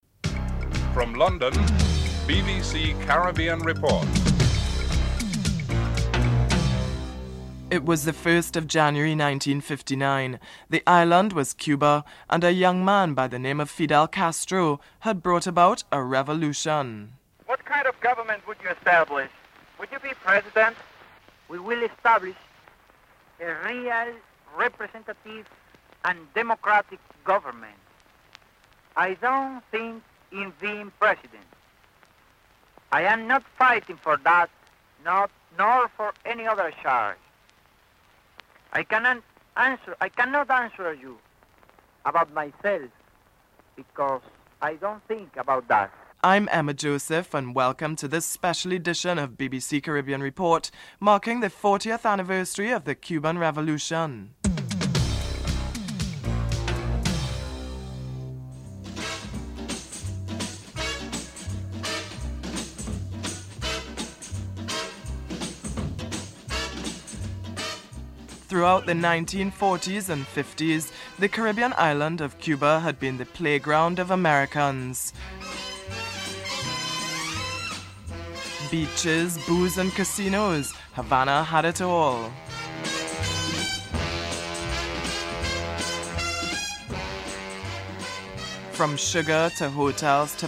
1. Headlines (00:00-00:58)